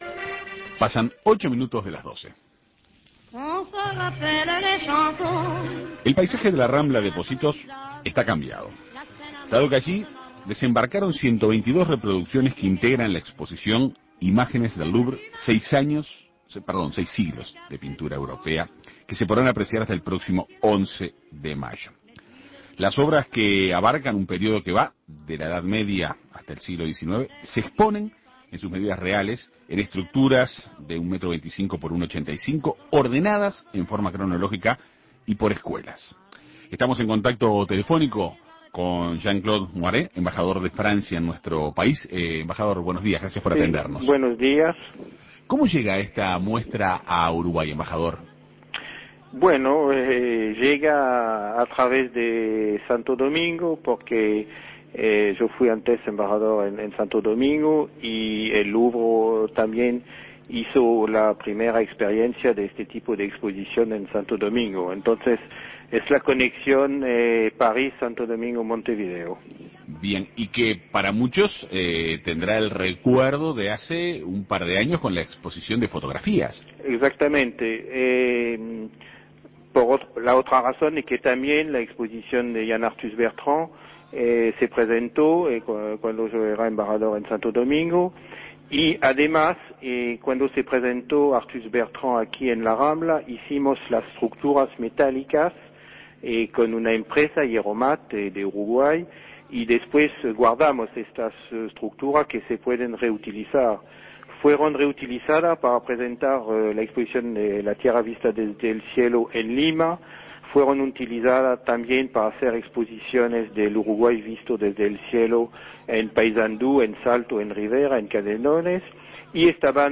En Perspectiva Segunda Mañana dialogo con Jean Claud Mouré, embajador francés en Uruguay, para conocer más sobre esta actividad.